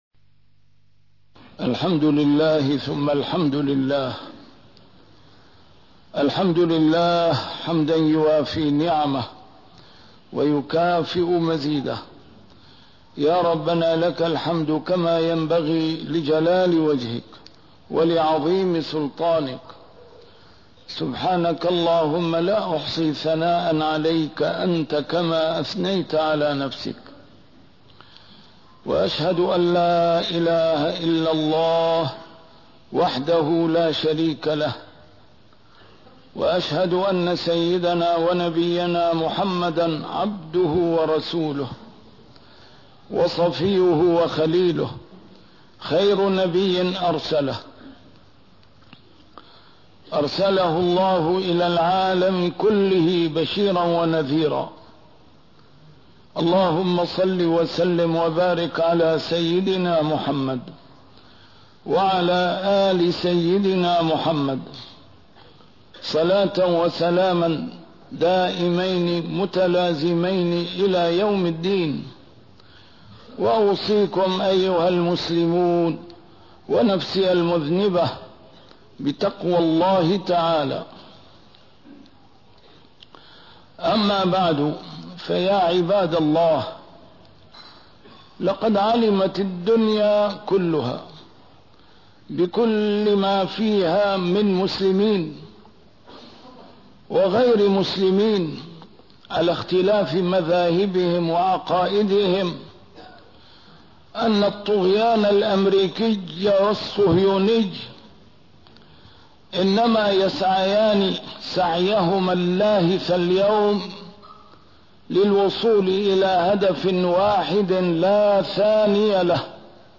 A MARTYR SCHOLAR: IMAM MUHAMMAD SAEED RAMADAN AL-BOUTI - الخطب - حصن الوعي الإسلامي